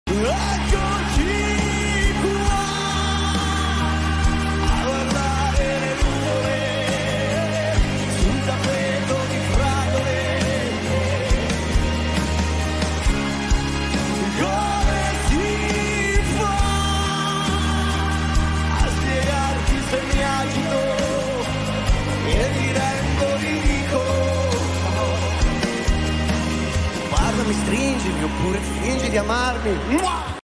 Segui il concerto in diretta